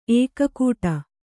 ♪ ēkakūṭa